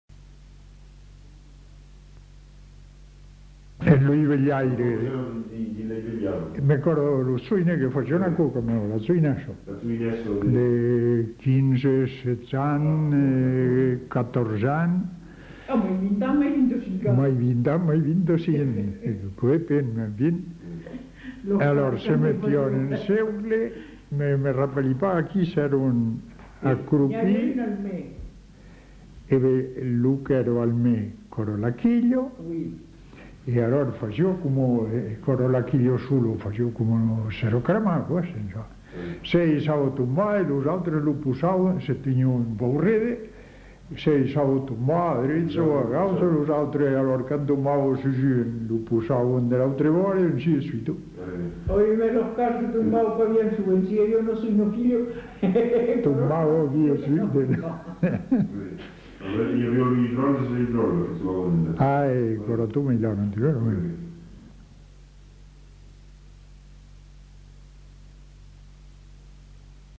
Aire culturelle : Haut-Agenais
Genre : témoignage thématique